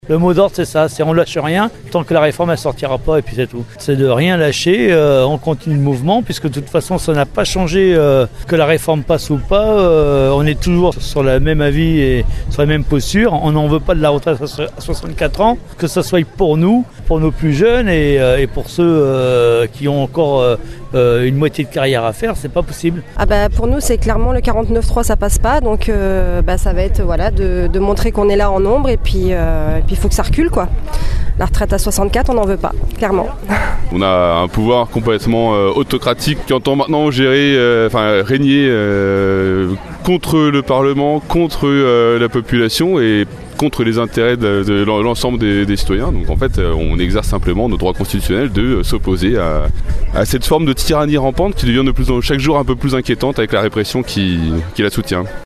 C’est là que nous avons rencontré ce midi plusieurs opposants à la réforme, bien décidés à en exiger le retrait.
Barbecue revendicatif ce midi à Rochefort avant la manif.